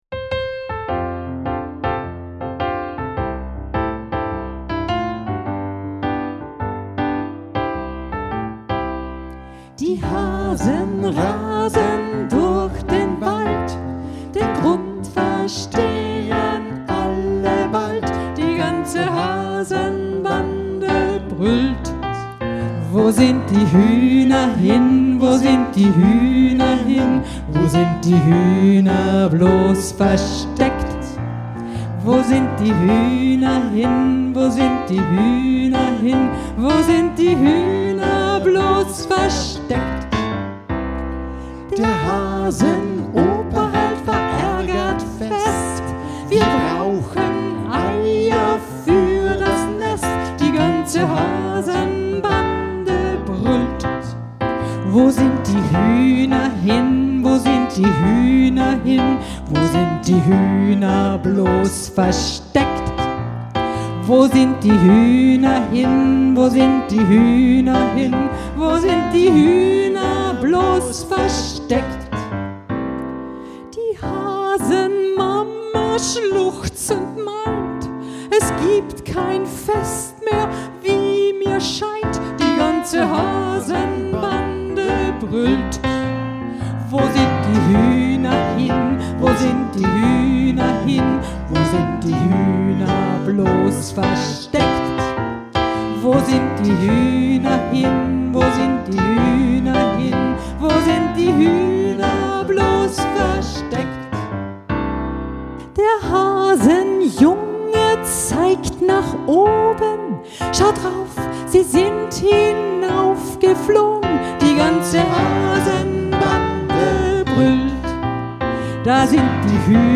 Klavier
Gesang